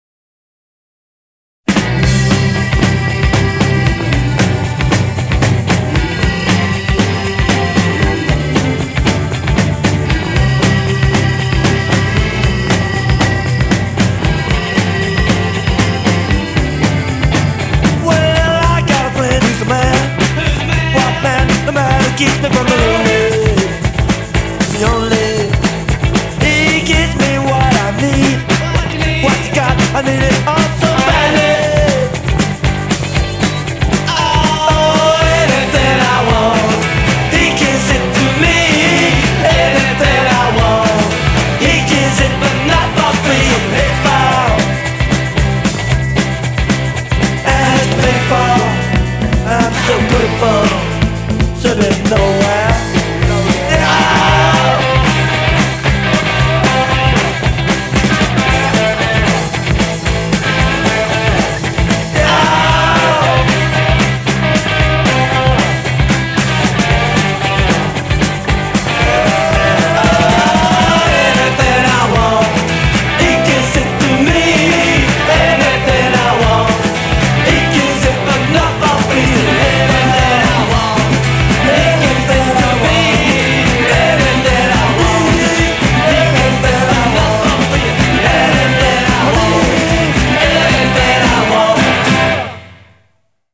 BPM115
Audio QualityPerfect (High Quality)